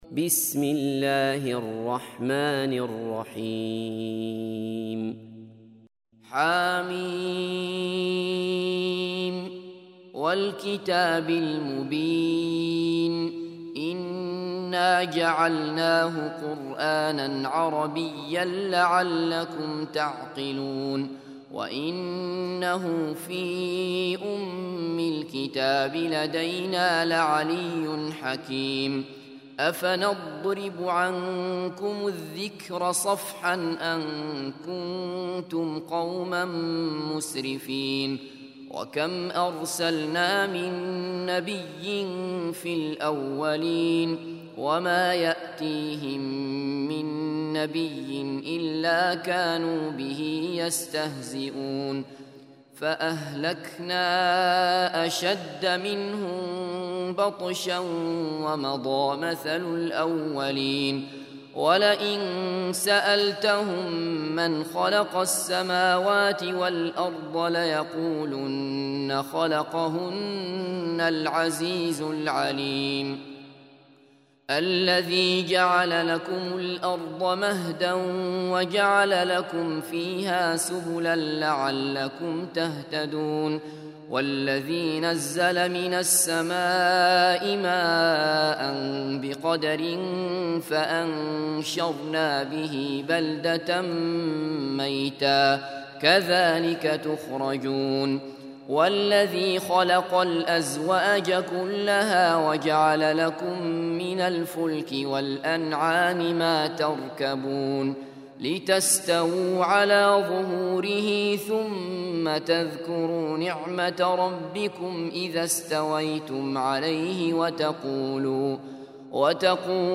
43. Surah Az-Zukhruf سورة الزخرف Audio Quran Tarteel Recitation
Surah Sequence تتابع السورة Download Surah حمّل السورة Reciting Murattalah Audio for 43. Surah Az-Zukhruf سورة الزخرف N.B *Surah Includes Al-Basmalah Reciters Sequents تتابع التلاوات Reciters Repeats تكرار التلاوات